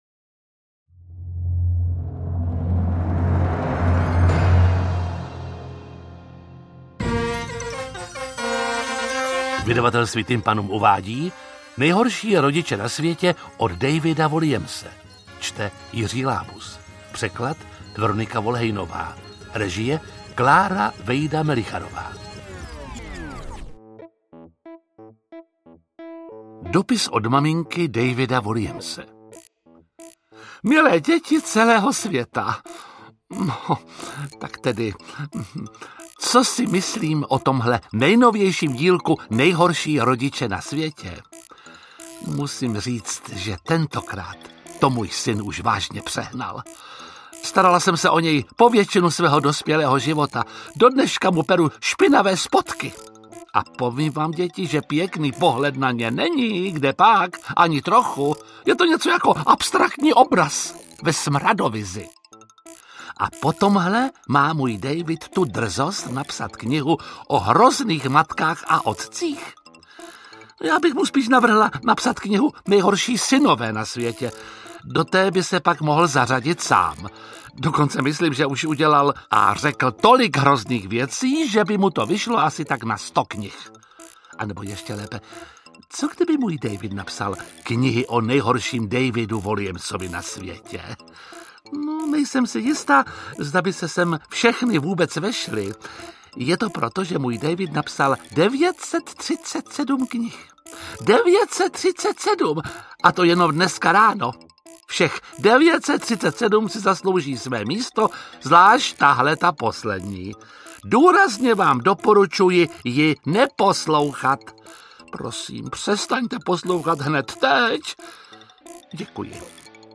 Interpret:  Jiří Lábus